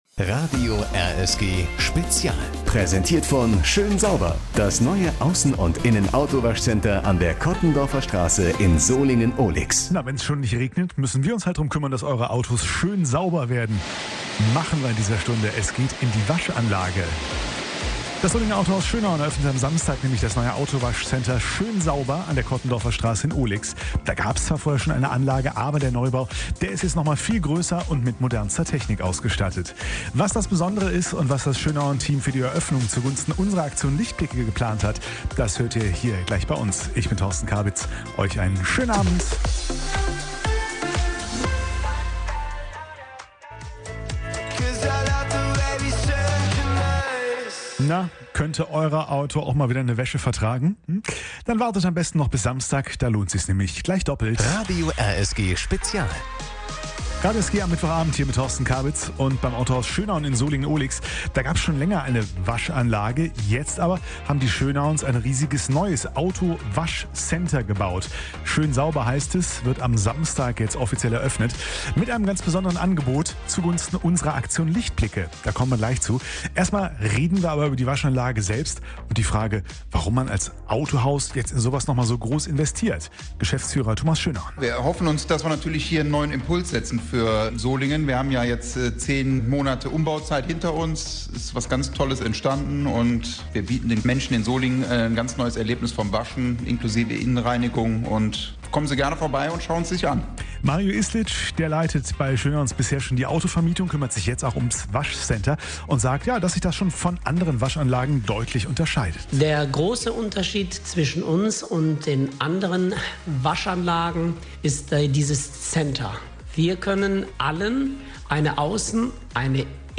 Um das neue Waschcenter und die Eröffnungsaktion ging es am Mittwochabend (26. Oktober) auch in einem RSG-Spezial, das hier nochmal nachhören könnt.
mitschnitt_schoensauber.mp3